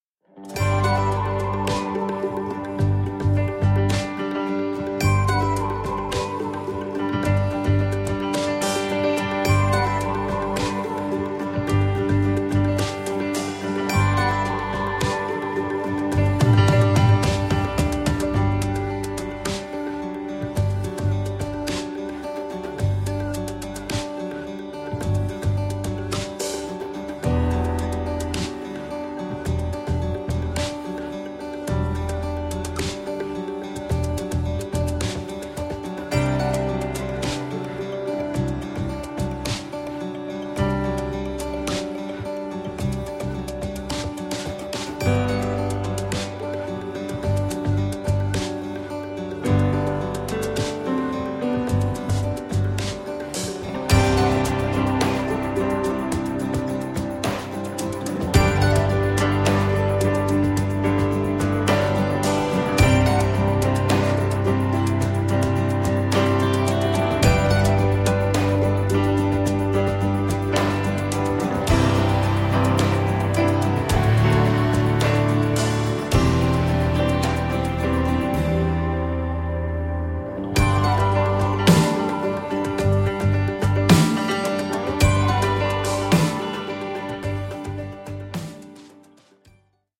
Das Playback-Album zur gleichnamigen Produktion.